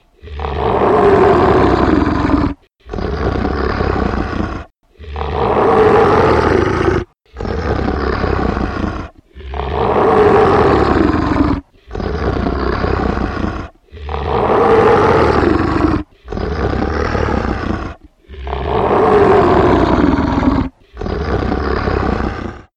Рингтон Звук рычания оборотня
Звуки на звонок